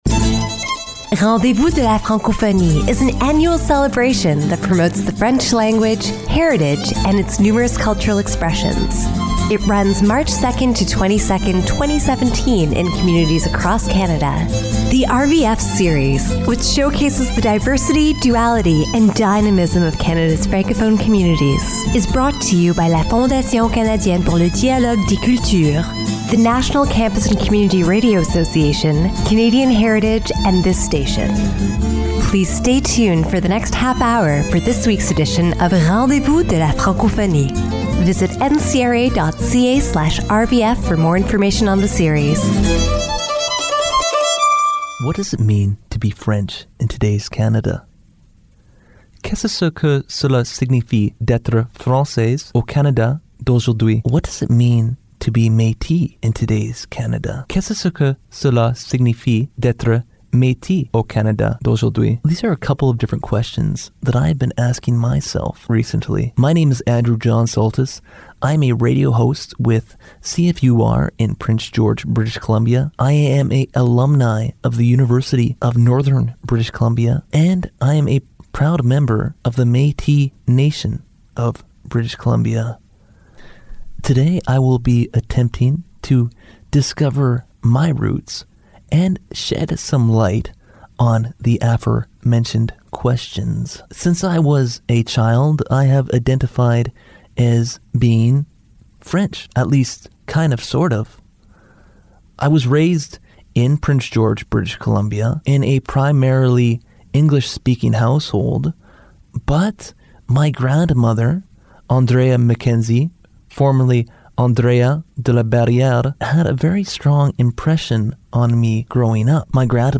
All music is traditional Metis folk.